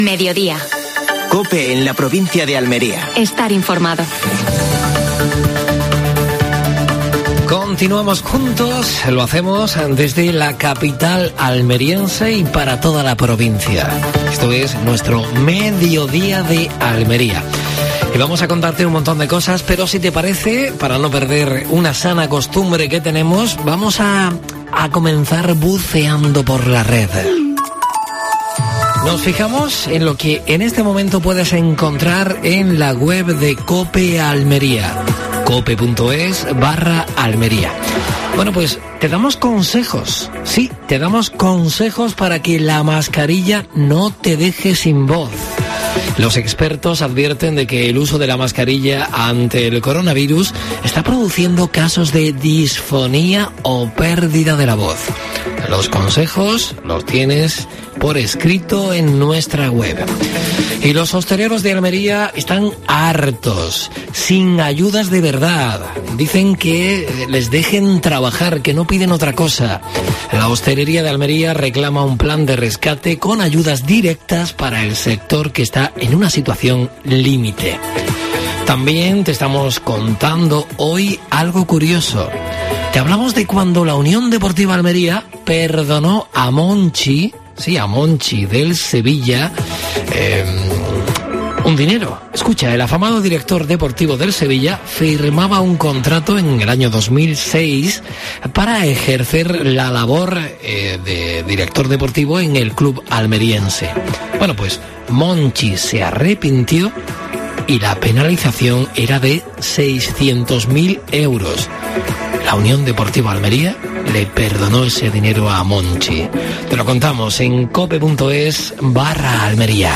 AUDIO: Actualidad en Almería. Entrevista al alcalde de Berja (José Carlos Lupión), en la sección 'Tu puebo. Un lugar seguro'.